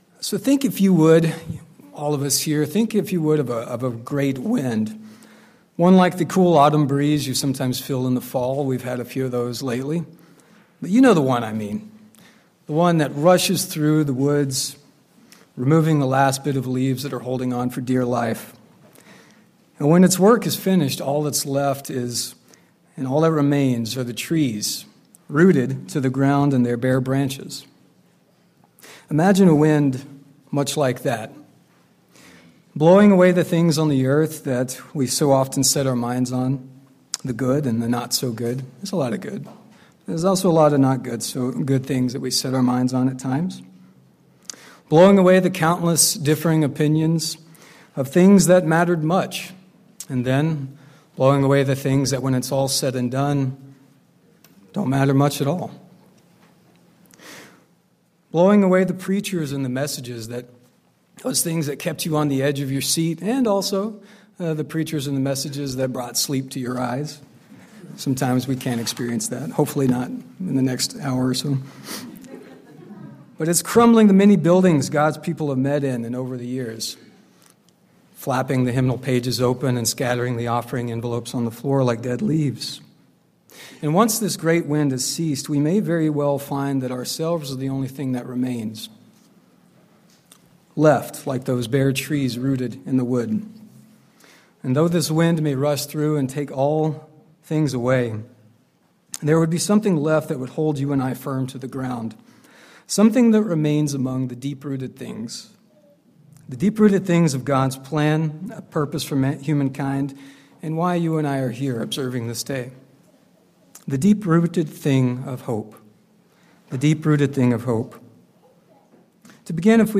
Sermons
Given in Spokane, WA Kennewick, WA Chewelah, WA